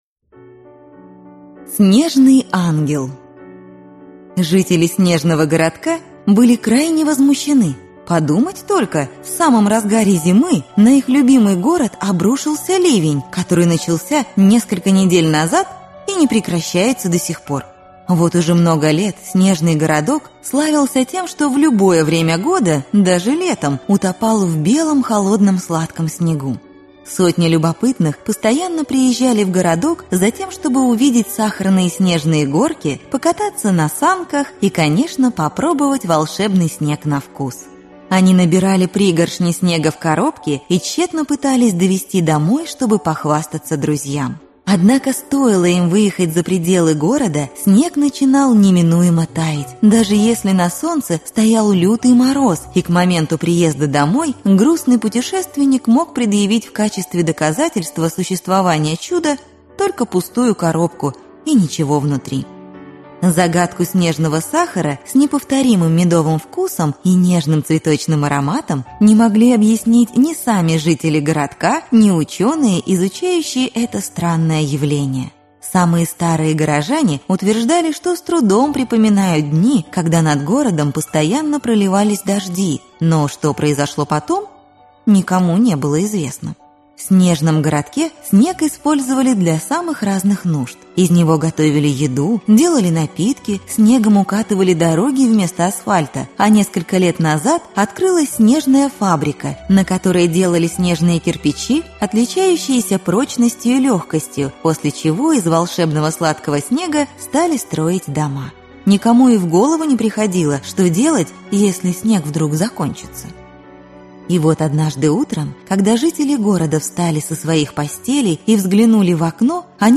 Аудиокнига Город ангелов (сказки об ангелах) | Библиотека аудиокниг